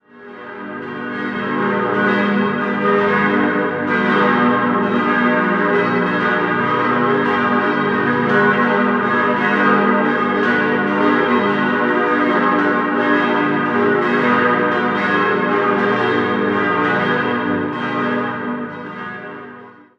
Die warmen Holztöne und die weiß verputzen Wände geben dem nachkonziliar gestalteten Innenraum eine wohnliche Atmosphäre. 6-stimmiges Geläute: gis°-h°-dis'-fis'-gis'-h' Alle sechs Glocken wurden 1968/69 von der Gießerei Emil Eschmann in Rickenbach gegossen.